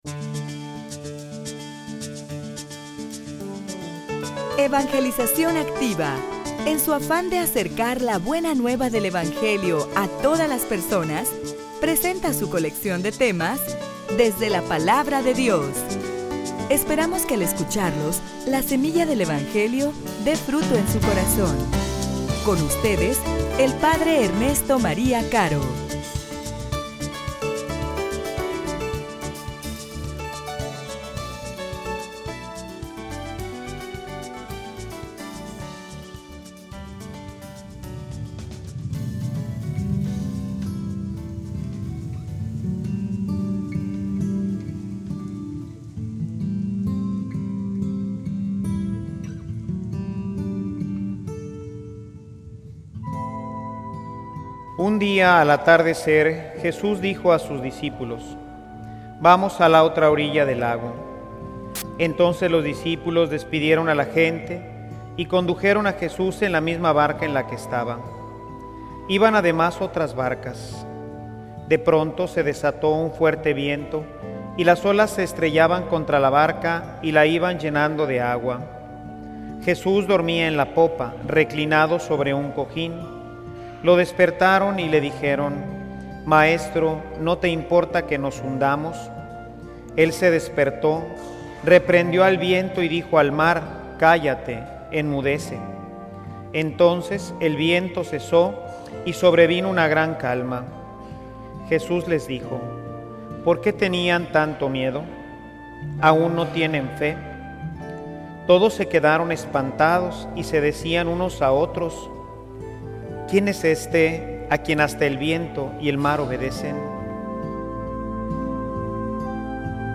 homilia_Testigos_del_poder_de_Dios.mp3